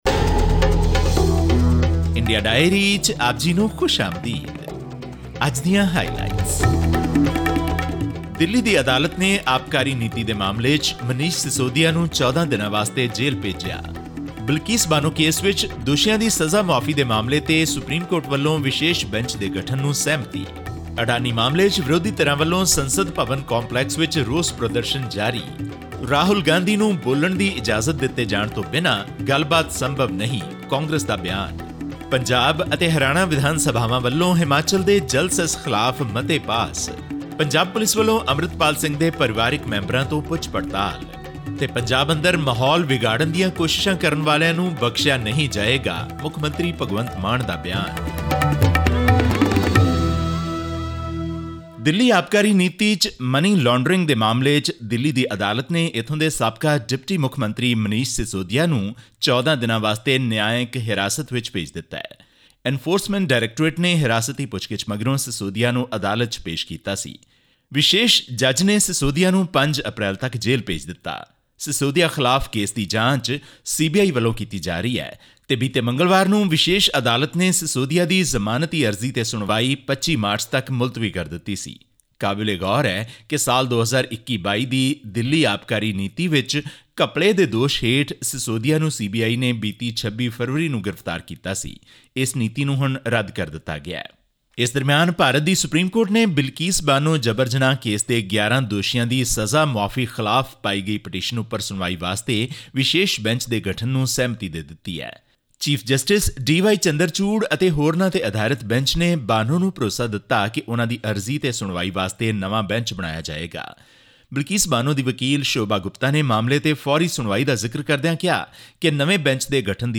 This and more in our weekly news bulletin from India.